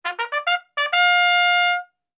3Bugle Charge.WAV